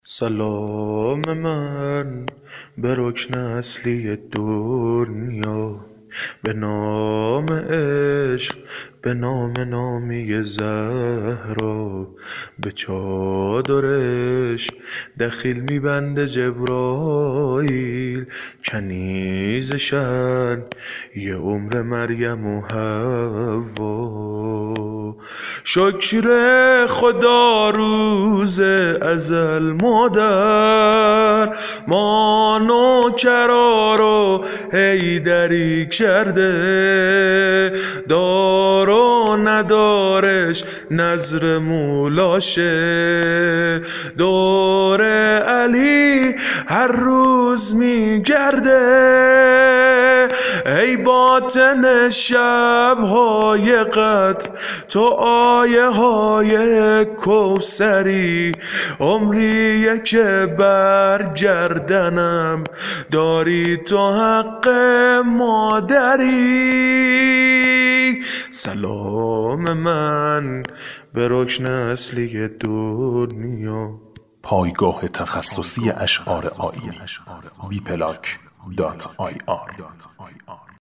شور ، زمینه